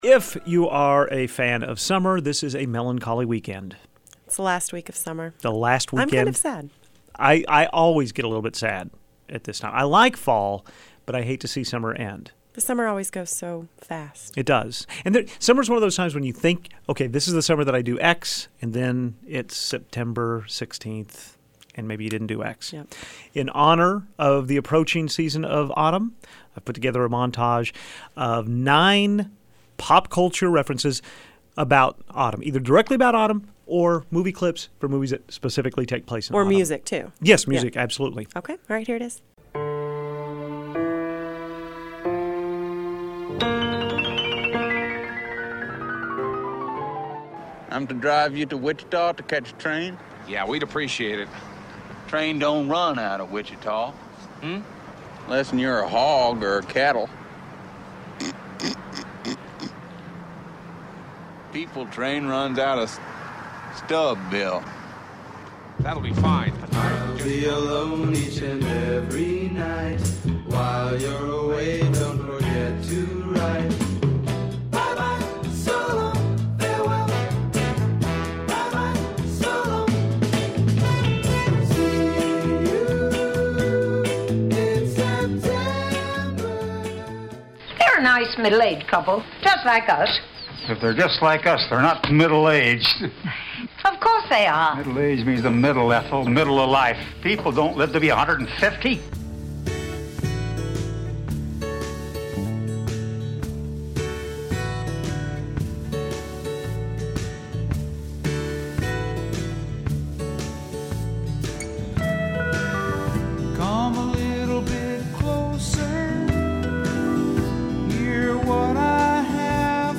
The autumnal montage of songs and movie clips featured, in order: "Autumn Leaves" by Roger Williams, a clip from the Steve Martin/John Candy film, Planes Trains and Automobiles, "See You in September" by The Happenings, a scene from the movie version of On Golden Pond, "Harvest Moon" by Neil Young, a bit from the annual TV special, It’s the Great Pumpkin, Charlie Brown, the theme often heard in the film franchise Halloween, a pivotal scene from To Kill a Mockingbird and Rod Stewart’s "Maggie May".